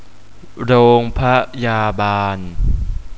Hospital - "Rong Pa Ya Bann"